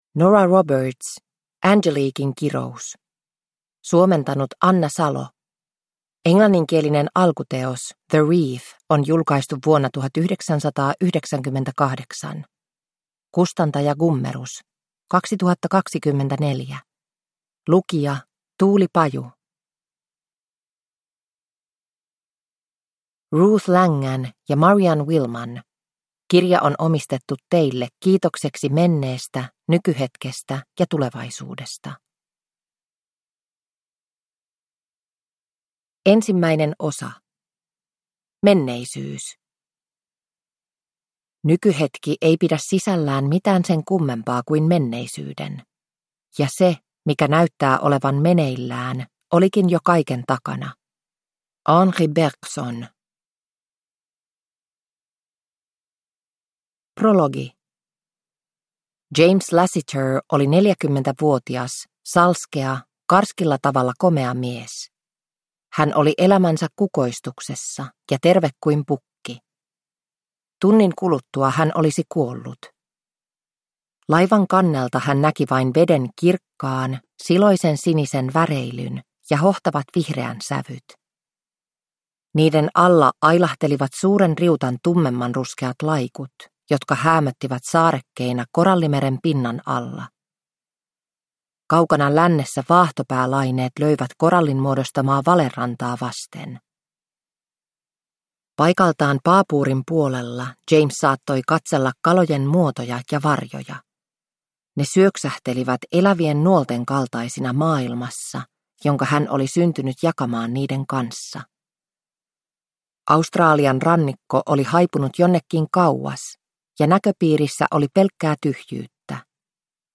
Angeliquen kirous – Ljudbok